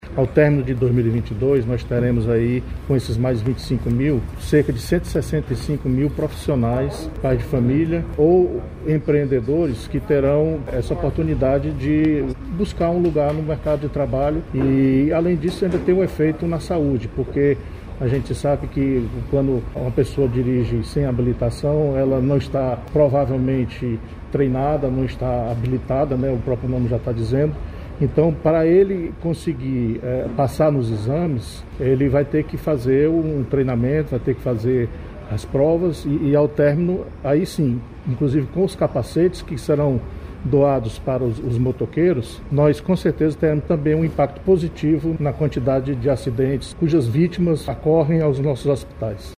Para o secretário da Infraestrutura, Lúcio Ferreira Gomes, a nova etapa do Programa CNH popular para colocar novos profissionais no mercado de trabalho além de repercussão positiva na saúde pública do Ceará.